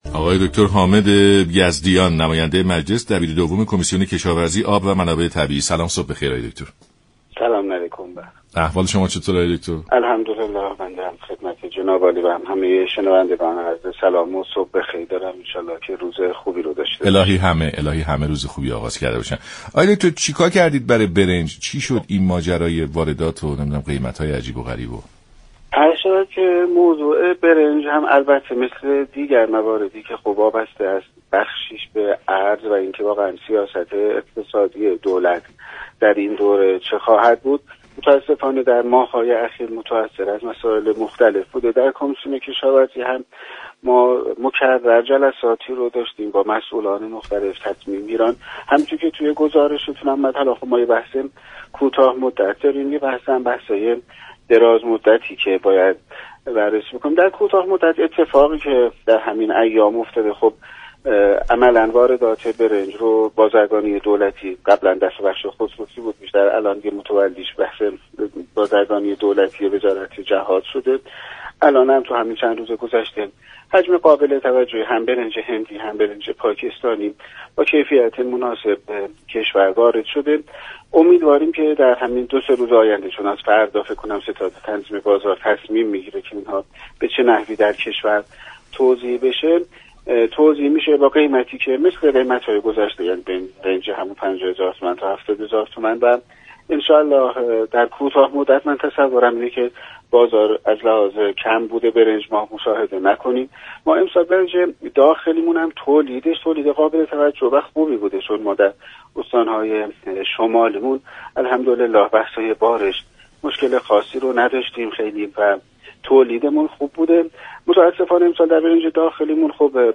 دبیر دوم كمیسیون كشاورزی و آبخیزداری و منابع‌طبیعی در برنامه سلام‌صبح‌بخیر گفت: قیمت برنج پاكستانی تغییری نكرده و با قیمت 50 تا 70 هزار تومان فروخته خواهد شد.